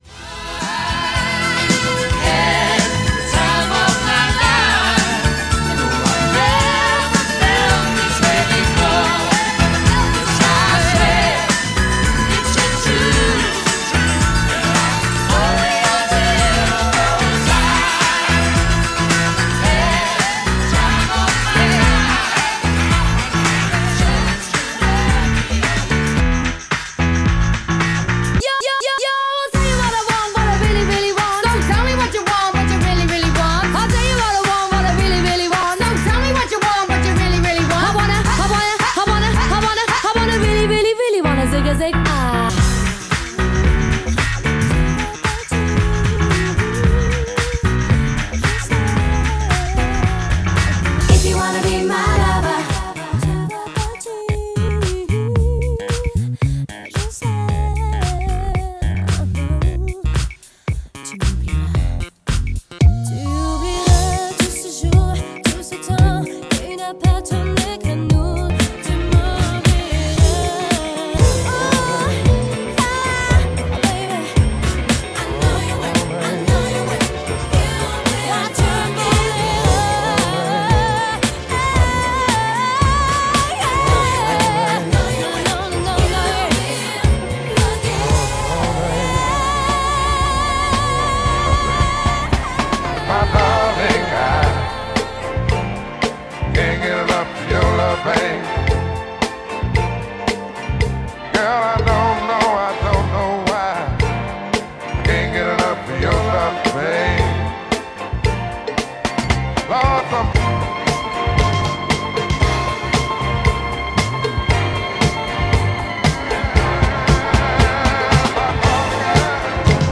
lors d'un Mariage